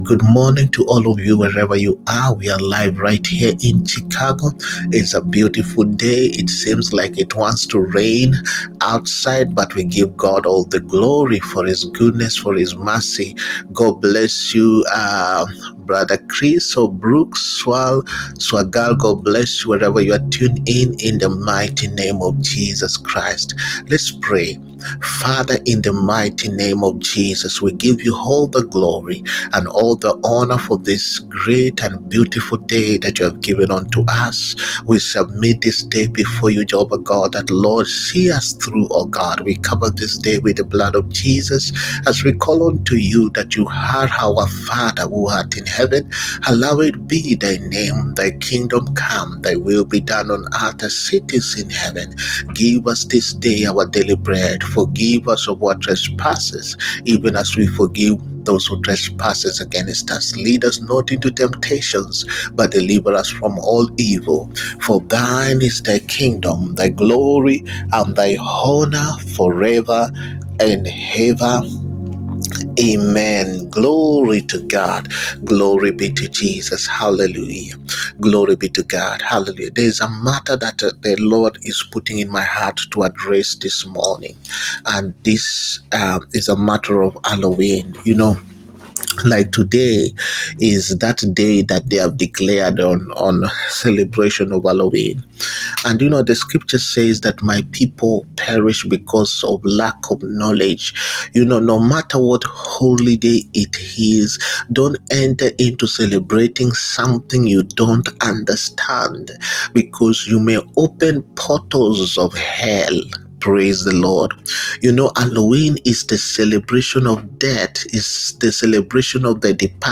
MORNING DEVOTION AND PRAYERS. THEME: WHY WE SHOULD NOT CELEBRATE HALLOWEEN.
MORNING-DEVOTION-AND-PRAYERS.-WHY-YOU-SHOULD-NOT-CELEBRATE-HALLOWEEN.mp3